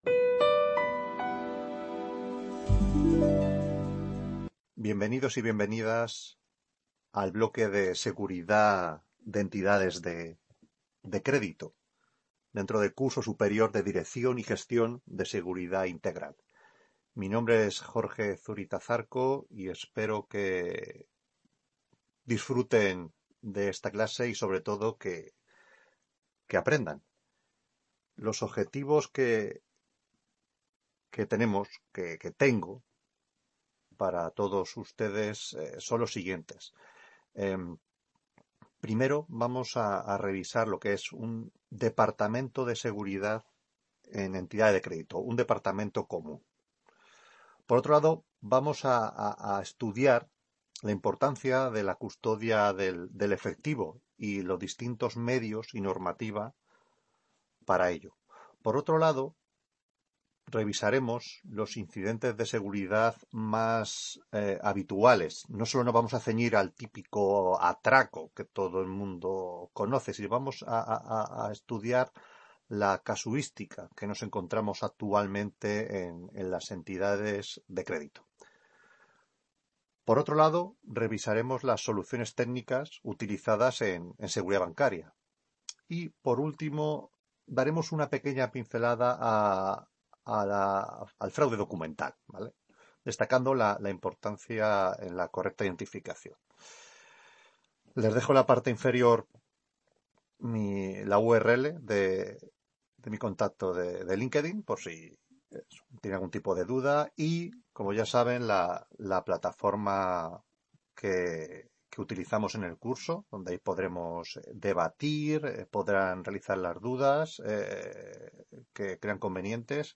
Ponencia sobre Seguridad en Entidades de Crédito - 1ª… | Repositorio Digital